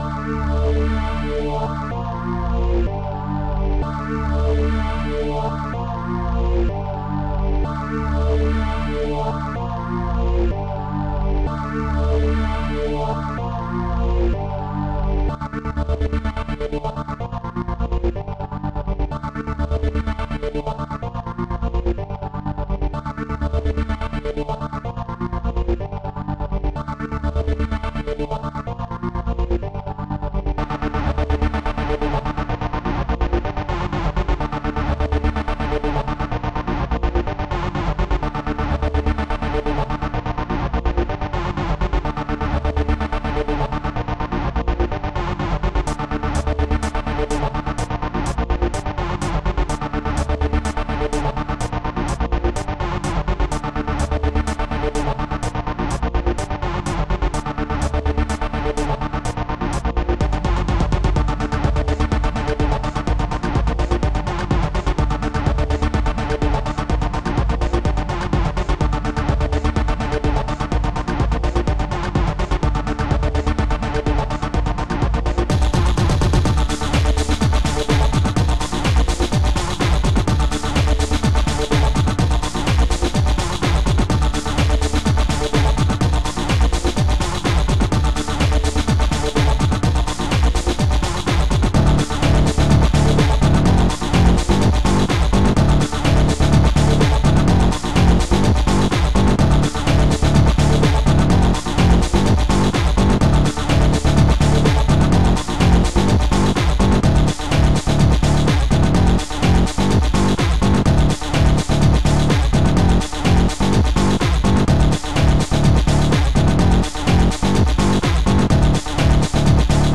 the sublime trance !!